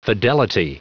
Prononciation du mot fidelity en anglais (fichier audio)
Prononciation du mot : fidelity